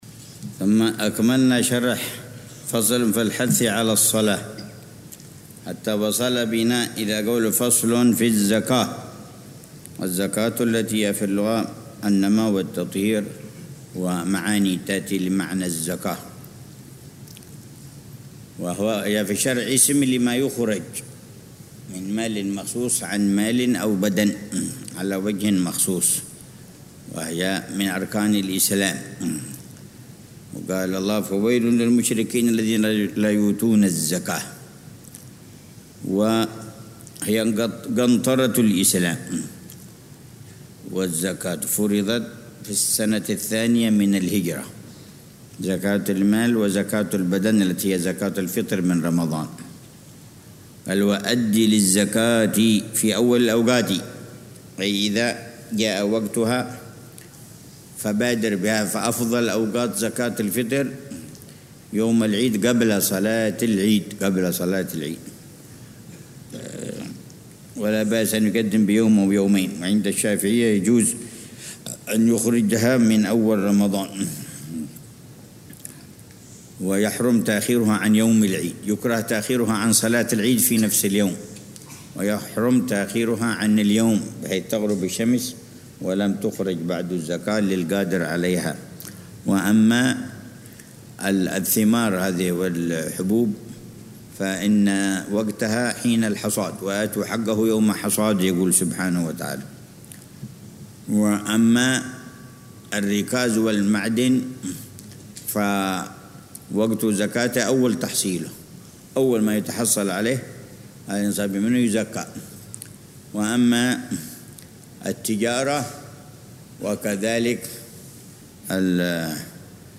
الدرس الثاني والعشرون ( 12 صفر 1447هـ)